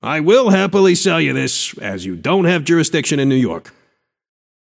Shopkeeper voice line - I will happily sell you this, as you don’t have jurisdiction in New York.